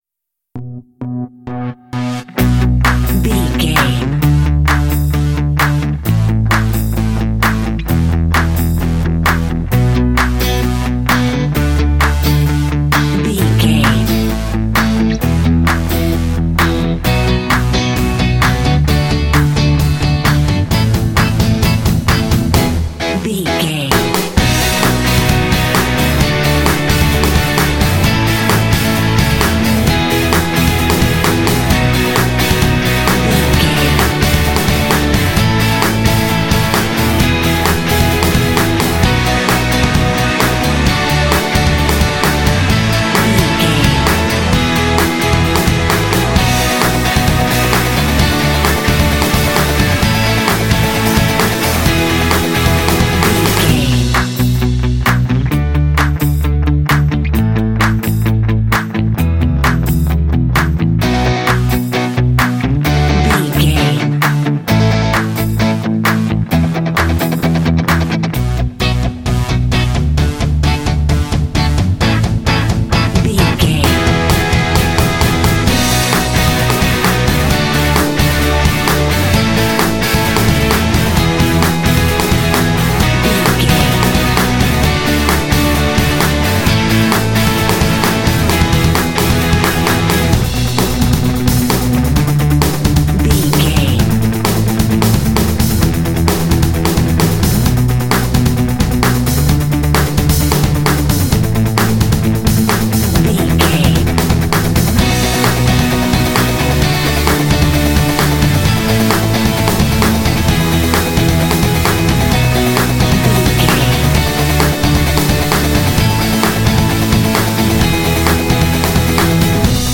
Aeolian/Minor
energetic
optimistic
lively
repetitive
drums
bass guitar
electric guitar
80s